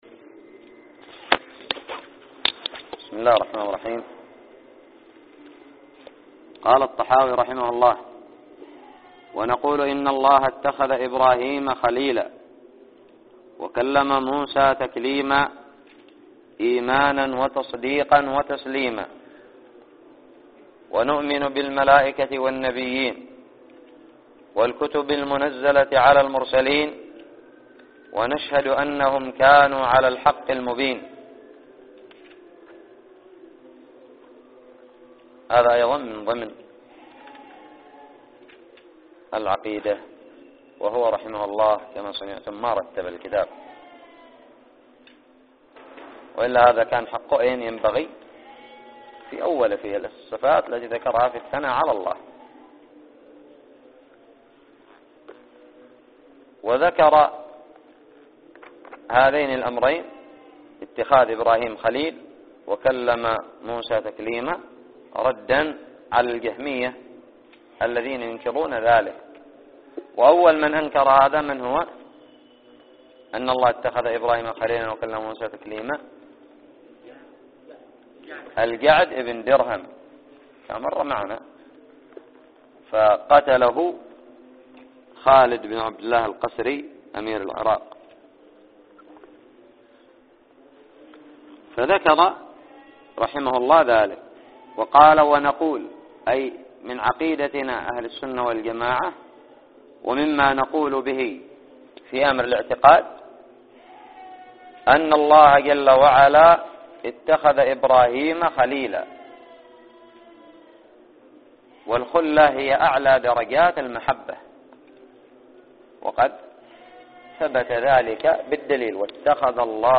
الدرس السادس والعشرون من شرح العقيدة الطحاوية
ألقيت في دار الحديث بدماج